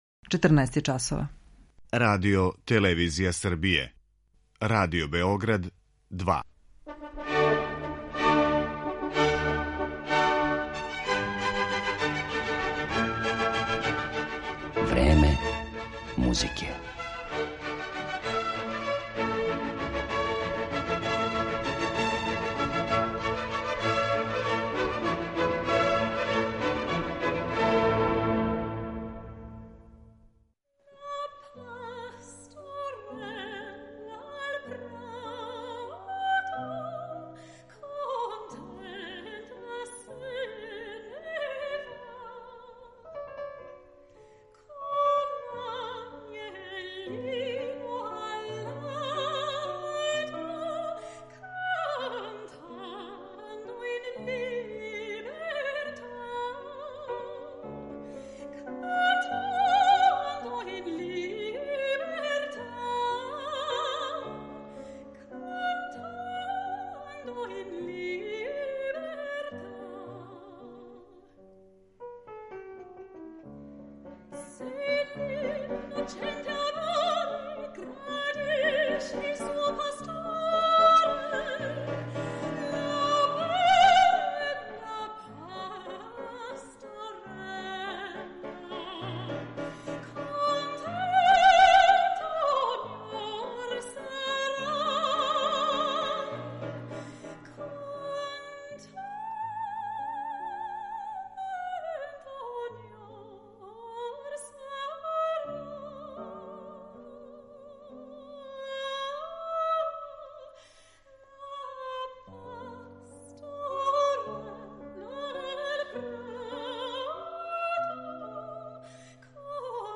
Керолајн Семпсон пева немачки лид 18. века
Композиције Августа Хербинга, Карла Филипа Емануела Баха, Фридриха Флајшера, Кристијана Волфа и Волфганга Амадеуса Моцарта изводиће једна од најатрактивнијих вокалних солисткиња данашњице, британски сопран Керолајн Семпсон.